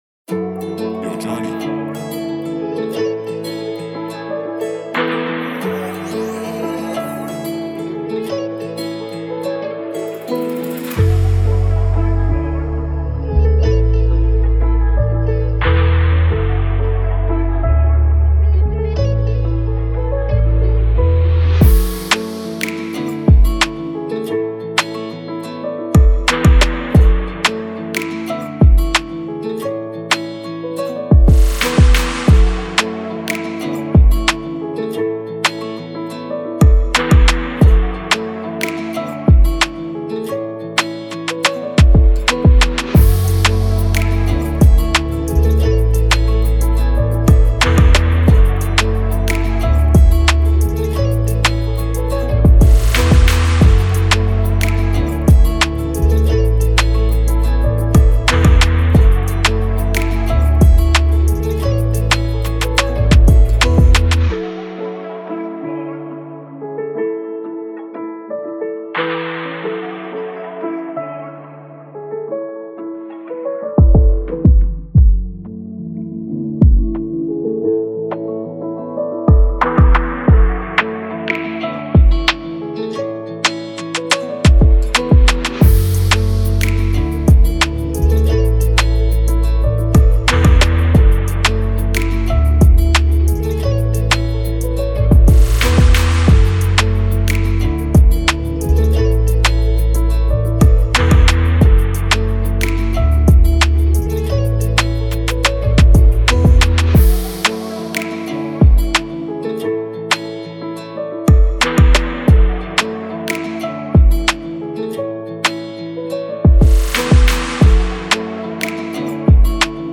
official instrumental
Dancehall/Afrobeats Instrumentals